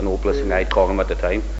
the-phonology-of-rhondda-valleys-english.pdf
2_4.5._an__opeless_singer.....mp3